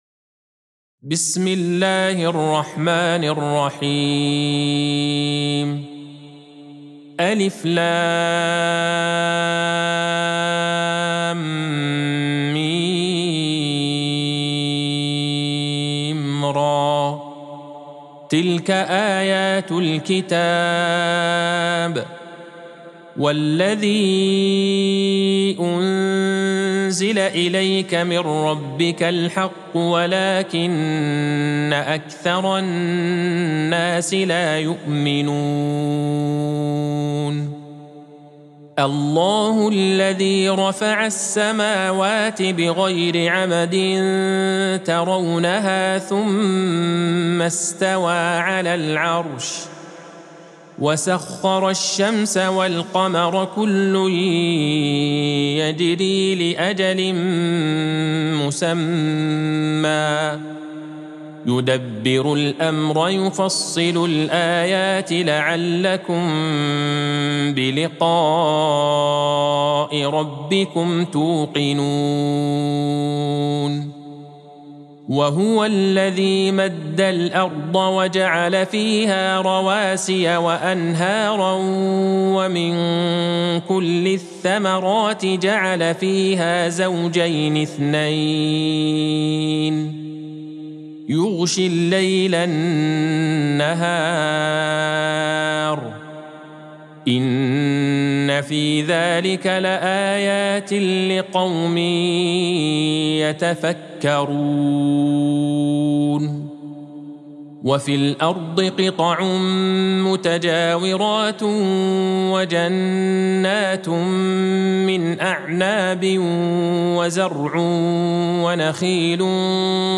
سورة الرعد Surat Ar-Ra'd | مصحف المقارئ القرآنية > الختمة المرتلة